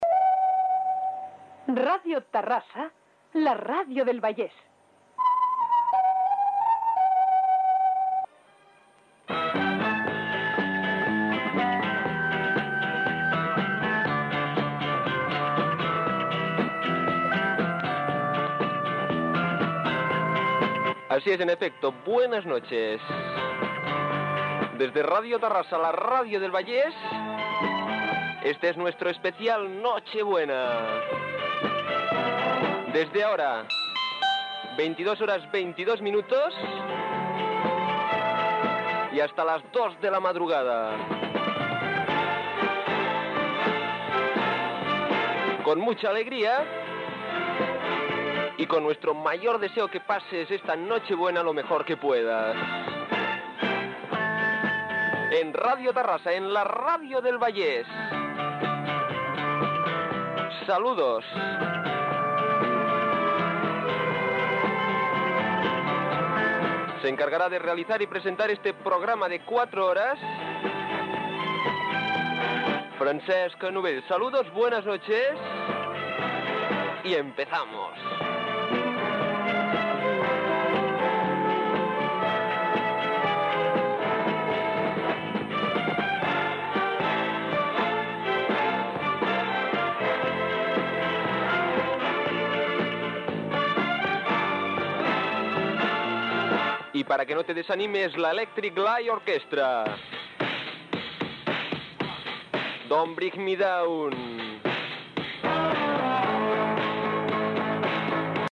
Indicatiu de l'emissora, hora i presentació del programa i d'un tema musical.
Musical